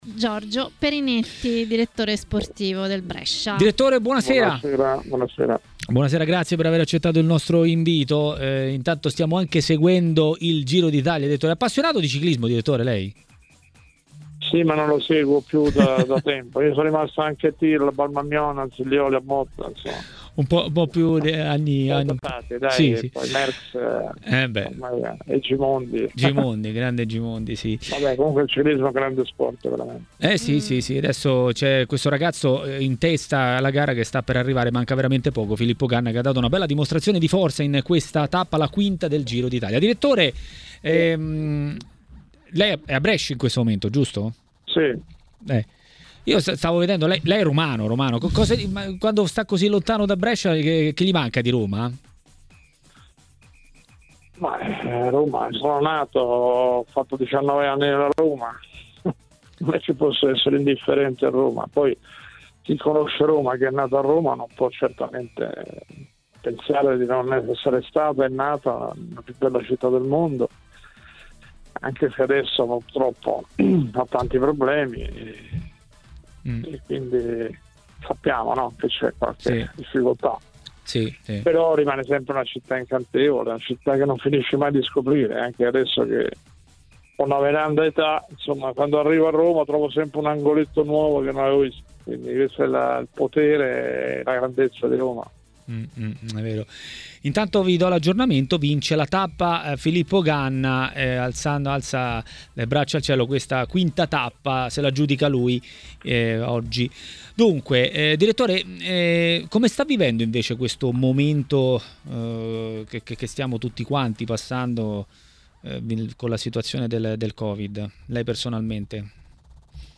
ha parlato dei temi del giorno a TMW Radio, durante Maracanà.